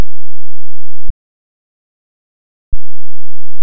Суть в том, что кривые автоматизации отрабатывают значение неторопясь. В моем случае мгновенная смена значения занимает 12ms.